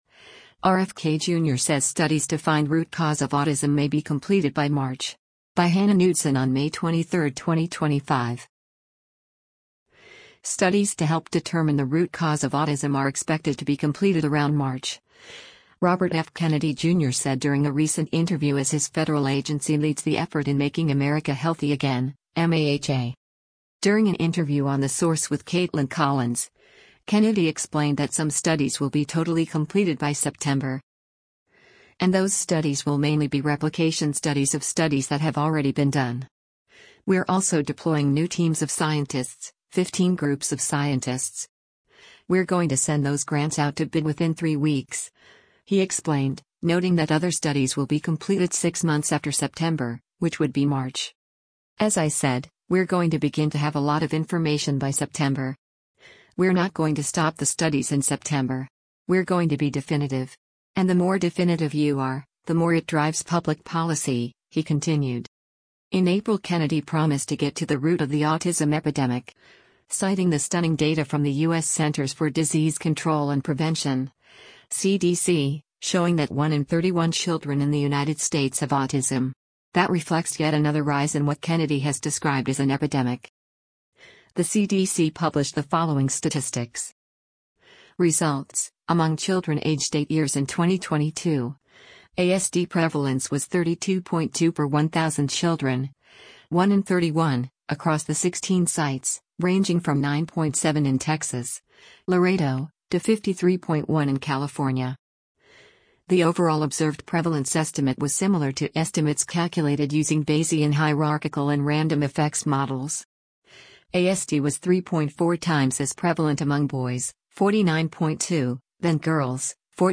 During an interview on The Source with Kaitlan Collins, Kennedy explained that some studies will be totally completed by September.